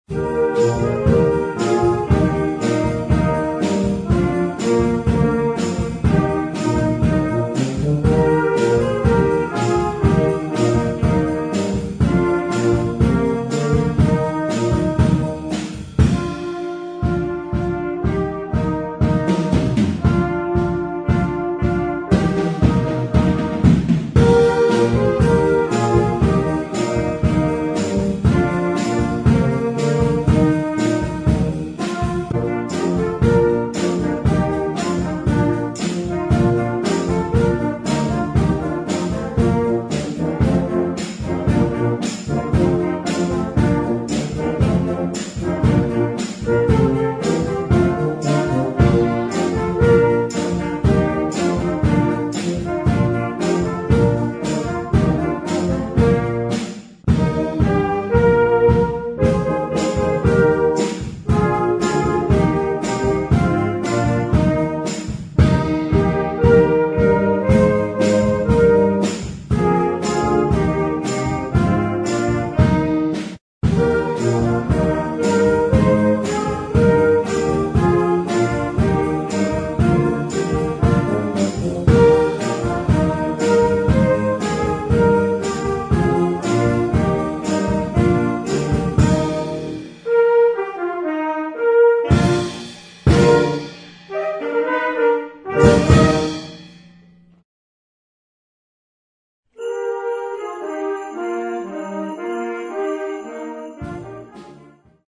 Sinterklaas et musique de Noël
Partitions pour ensemble flexible, 3-voix + percussion.